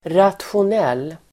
Uttal: [ratsjon'el:]